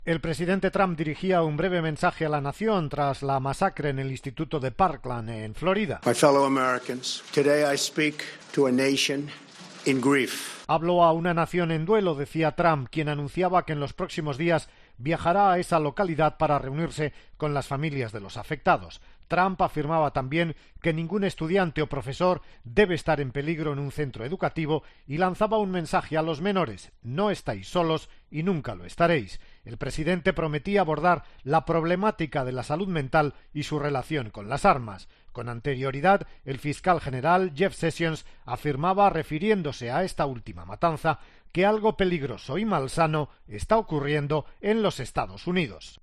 "Vamos a enfrentar el difícil problema de la salud mental", dijo Trump en su discurso dirigido a los estadounidenses, después del tiroteo que dejó 17 muertos y una quincena de heridos en un centro de educación secundaria de Parkland.